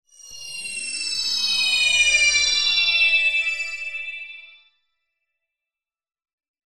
Efecto mágico
Sonidos: Especiales
Sonidos: Fx web